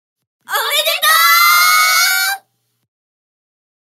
ボイス
ダウンロード 女性_「おめでとう」
パワフル